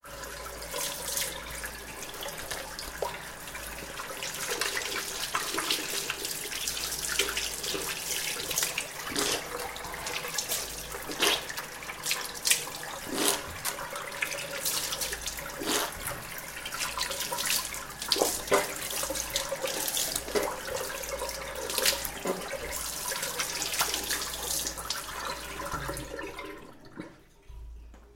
Звук подмывания в раковине: мытье подмышек и других пахучих мест